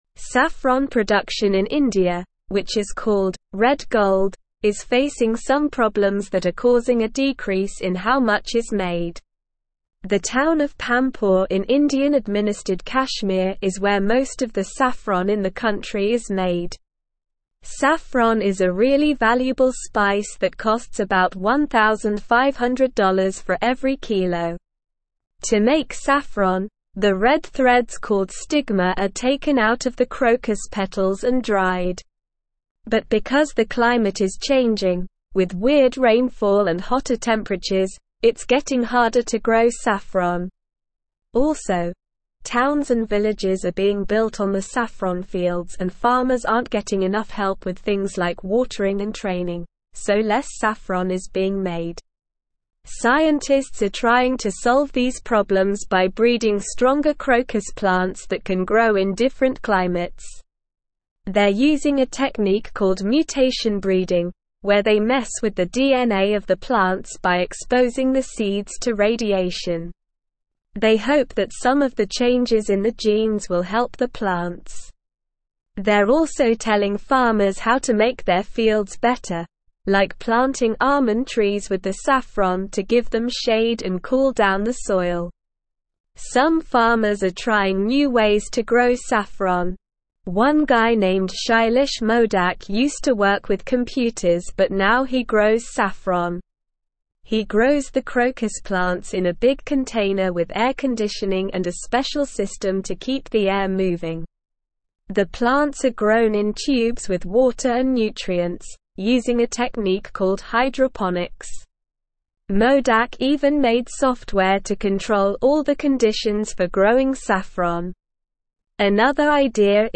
Slow
English-Newsroom-Upper-Intermediate-SLOW-Reading-Saffron-production-in-India-challenges-and-solutions.mp3